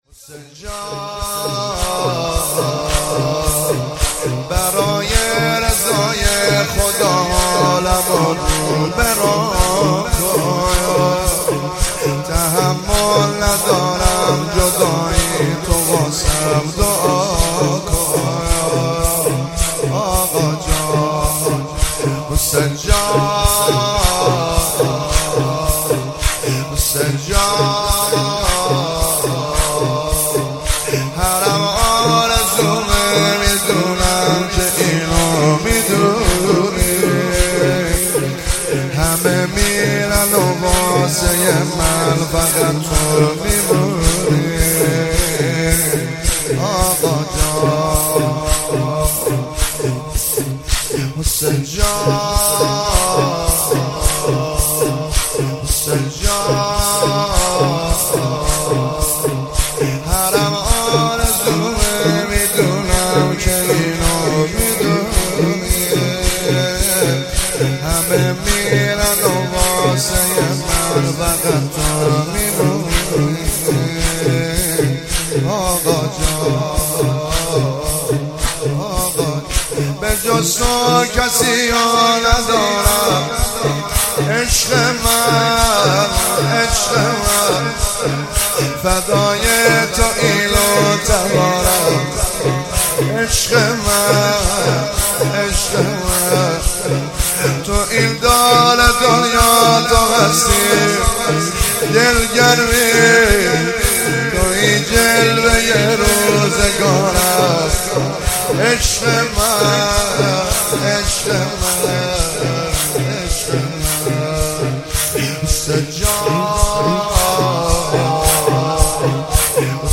ذکرتوسل مدت